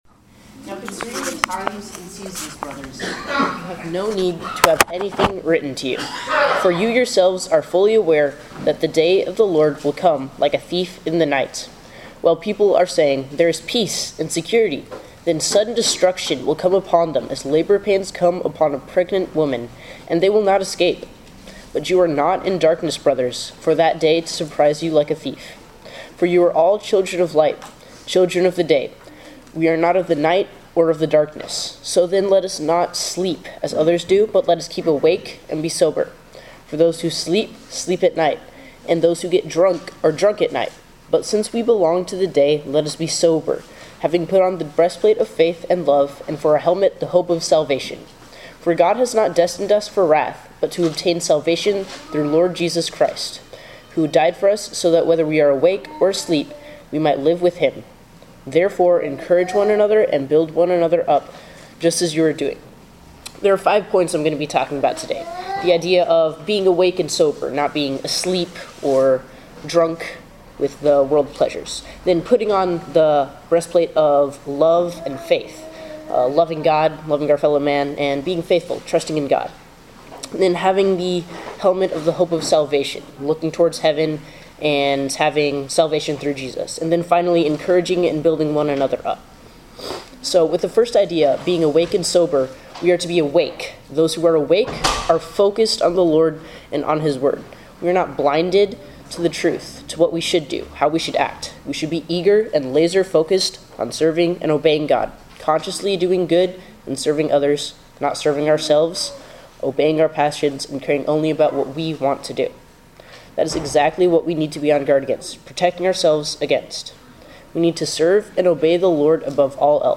Passage: 1 Thessalonians 5:1-10 Service Type: Sermon